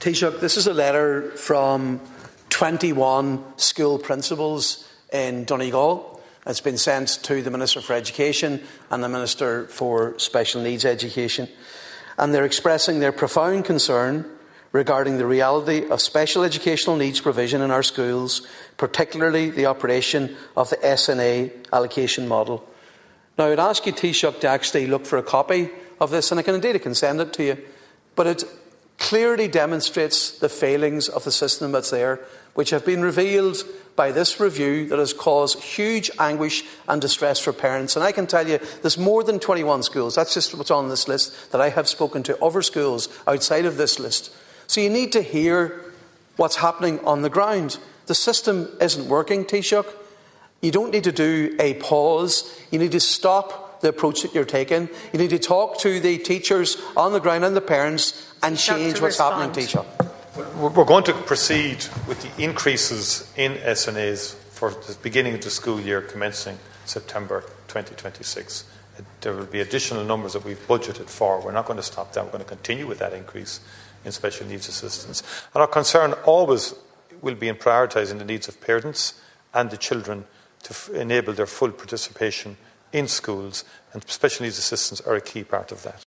The letter was produced in the Dail this afternoon by Donegal Deputy Padraig MacLochlainn, who told Taoiseach Michael Martin it’s a clear indication of the importance of this sector, and the potential harm that could be done by reducing the SNA allocation to schools.